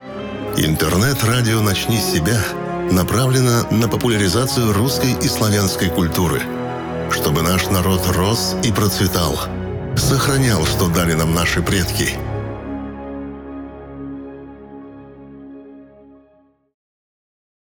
Тракт: Микрофон TLM 103,Sennheiser MKH 416-P48U3, карта YAMAHA 03,